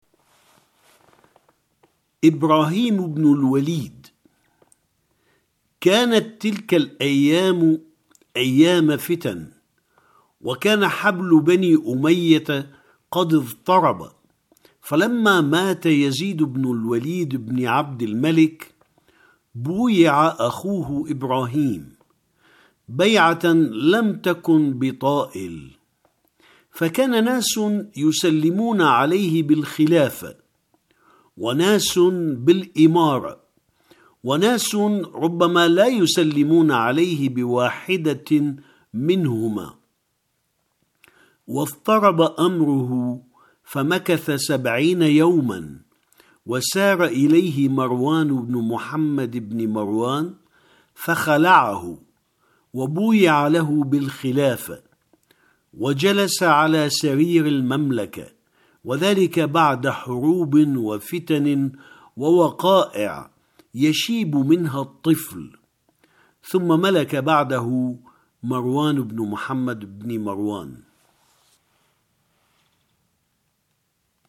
- Il se peut que des erreurs de prononciation se présentent dans les documenst sonores ; une version corrigée sera mise en ligne par la suite.